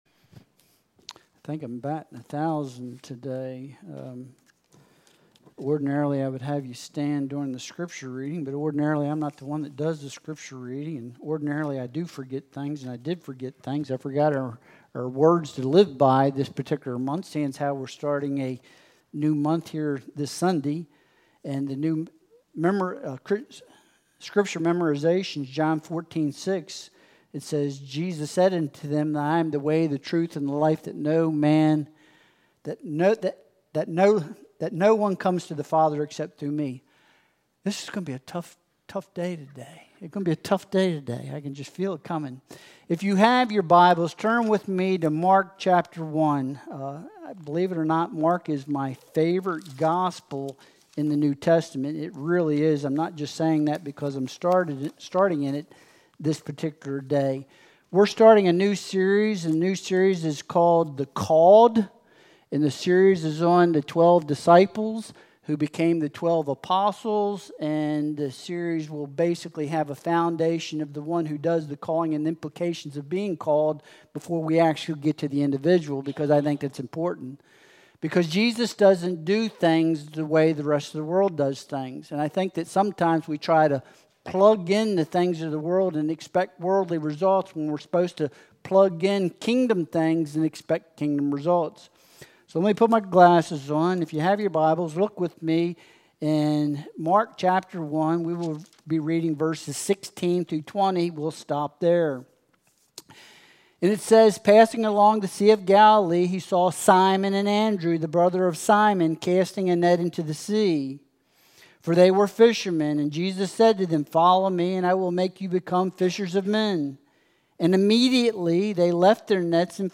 Mark 1.16-20 Service Type: Sunday Worship Service Download Files Bulletin « Are You Misfitted Enough?